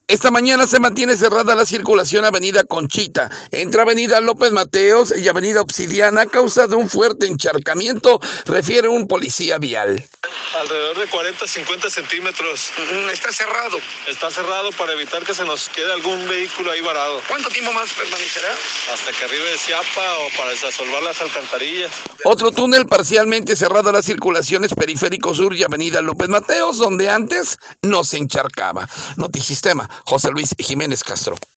Esta mañana se mantiene cerrada a la circulación avenida Conchita entre avenida López Mateos y avenida Obsidiana a causa de un fuerte encharcamiento, refiere un policía vial.